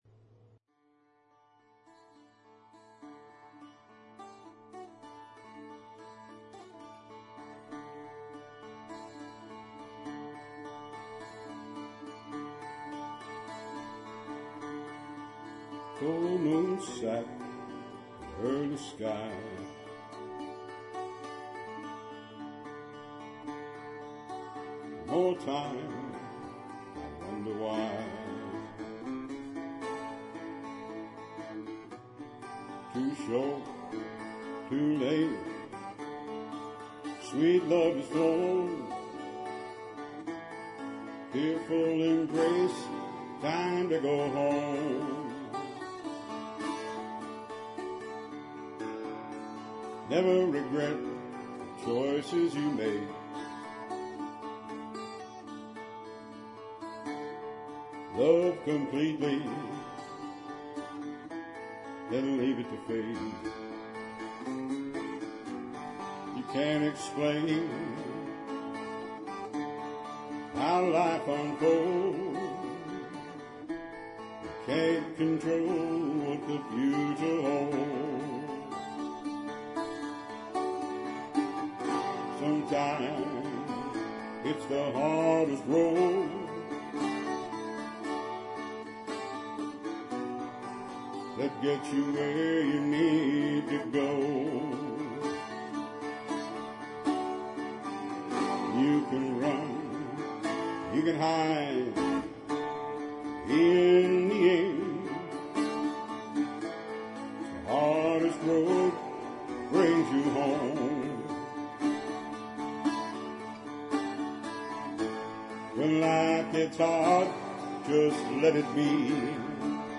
Key of D, Capod up ½ step
8/8 Time